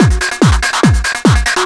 DS 144-BPM B5.wav